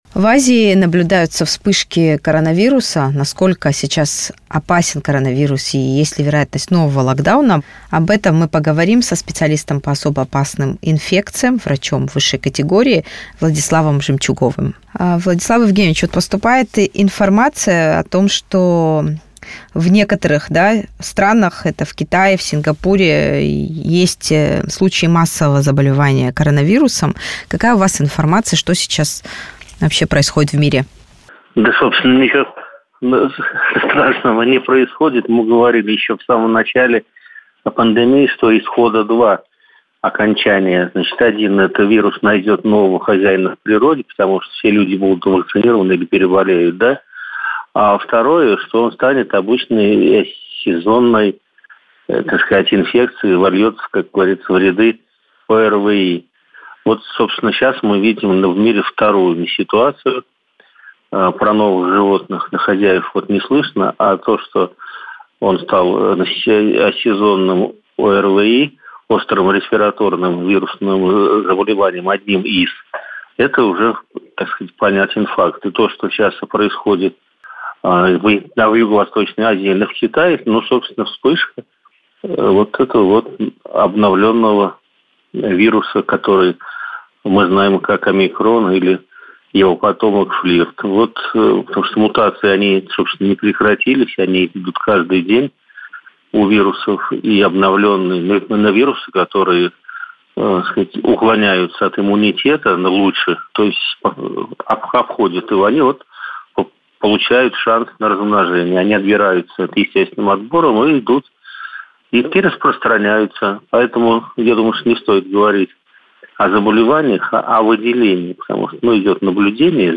Актуальный комментарий